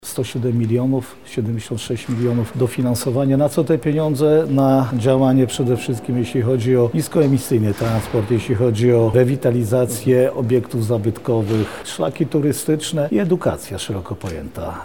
Jarosław Stawiarski– mówi Jarosław Stawiarski, Marszałek województwa lubelskiego.